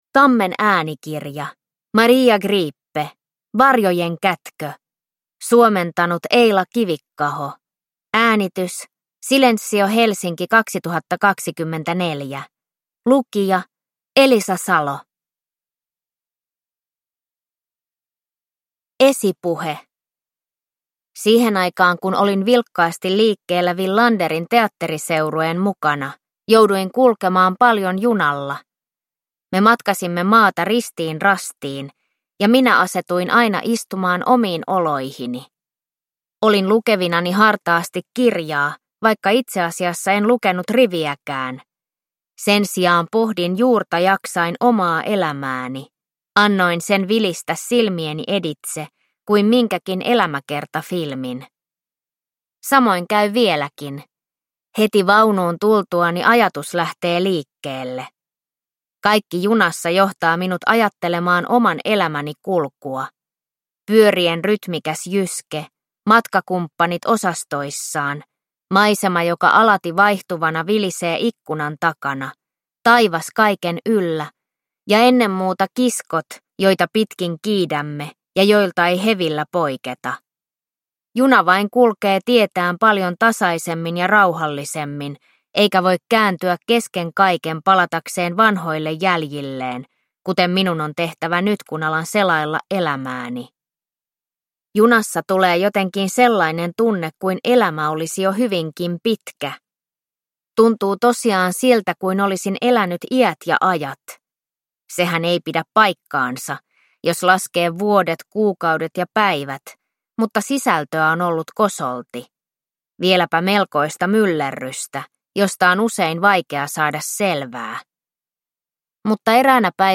Varjojen kätkö – Ljudbok